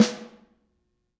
R_B Snare 04 - Close.wav